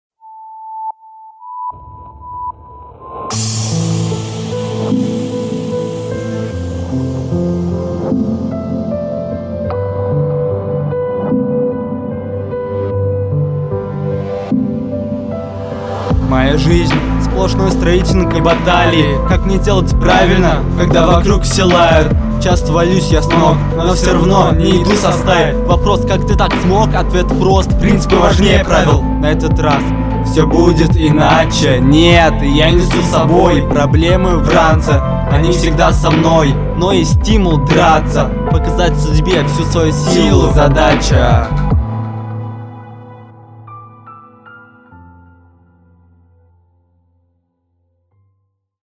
Бит хорош, исполнение совсем с ним не сочетается, ни ритмически, никак. Хотя есть фишка интересная у тебя в интонациях, потенциал виднеется уже сейчас, но уровня пока нет.